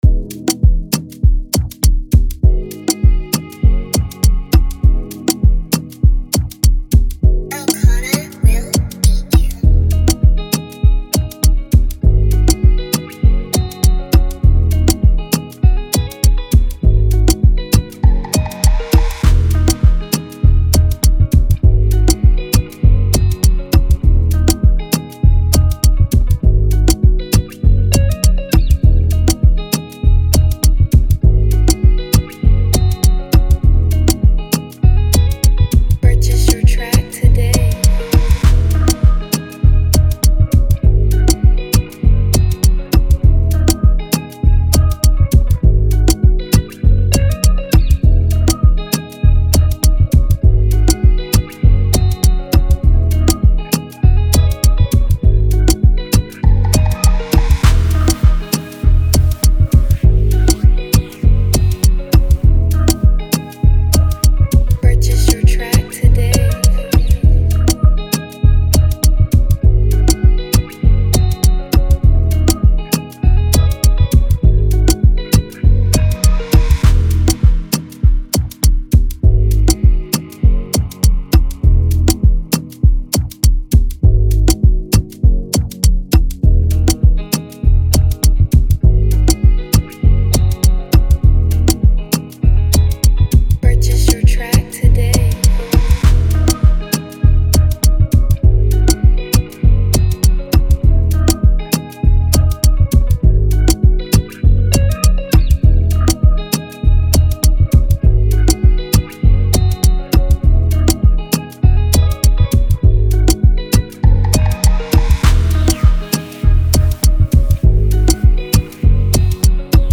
a vibrant Afrobeat instrumental
resonating at 96 bpm with an authentic Naija vibe.
type beat